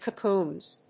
発音記号
• / kʌˈkunz(米国英語)
• / kʌˈku:nz(英国英語)